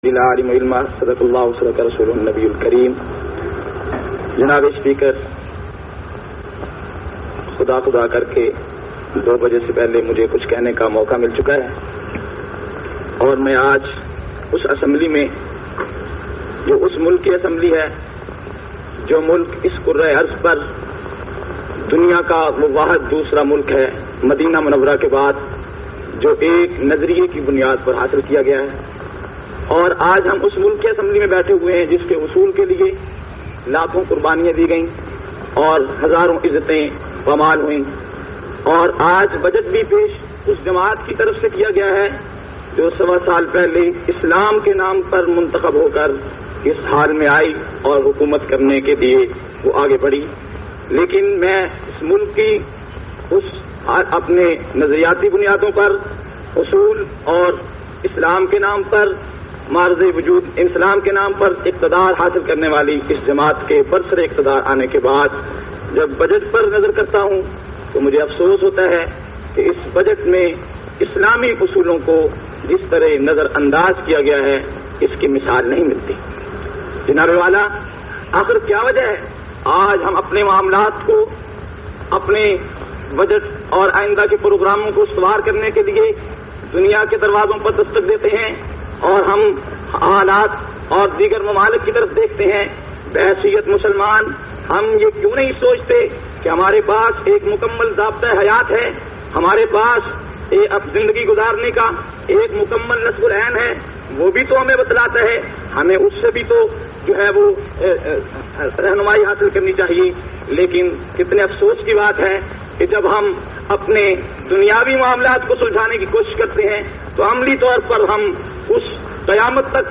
449- Qaomi Assembly Khutbat Vol 3.mp3